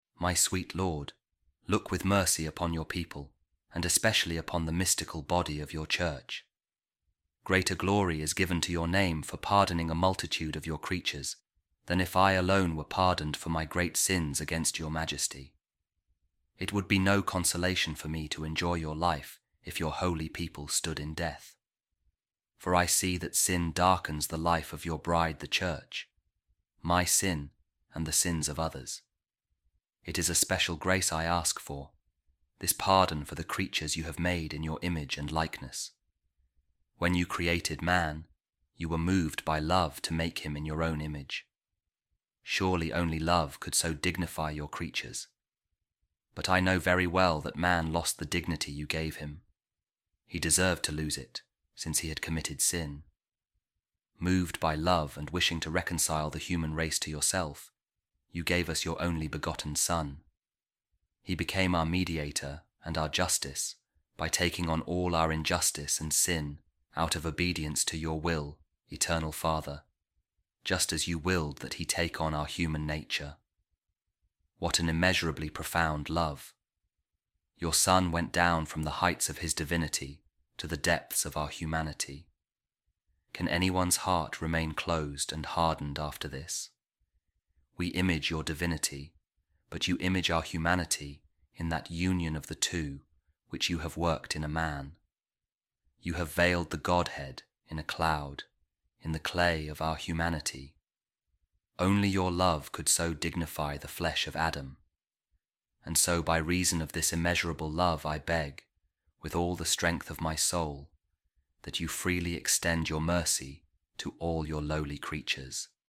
A Reading From The Dialogue Of Saint Catherine Of Siena On Divine Providence | Bonds Of Love